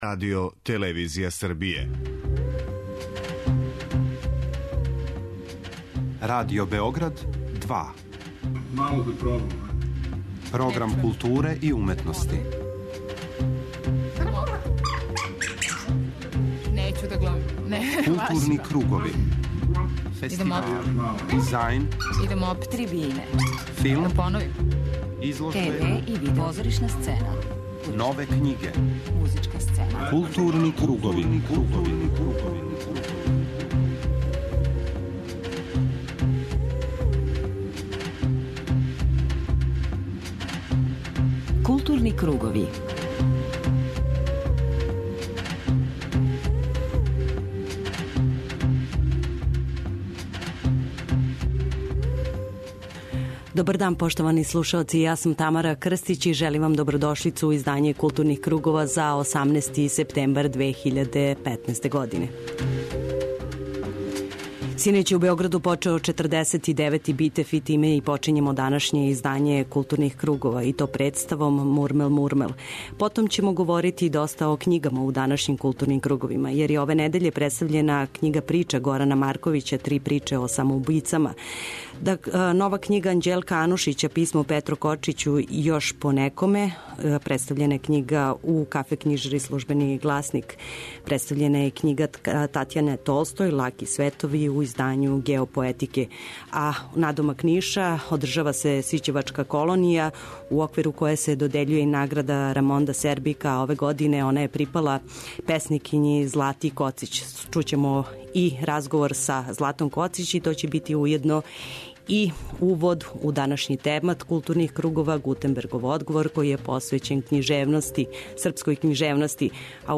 У огранку САНУ у Новом Саду ове недеље одржан је округли сто са темом "Савремена проза данас".